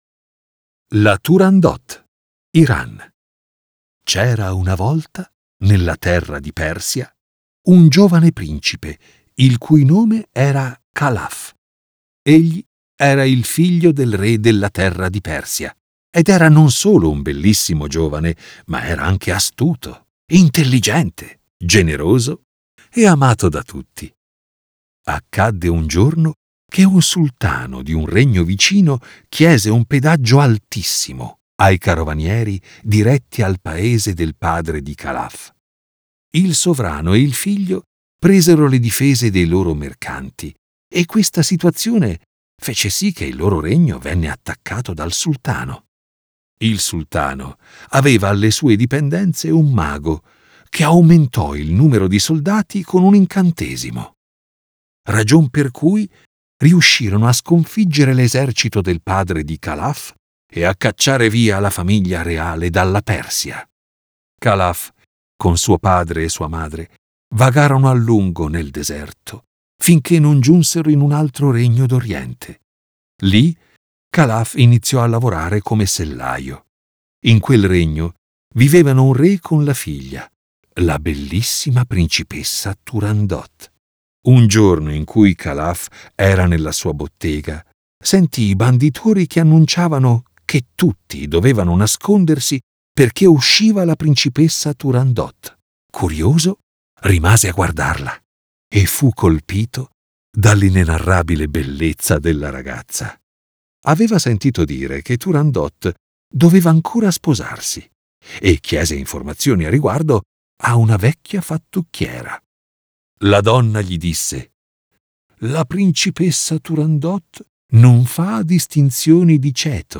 Voce narrante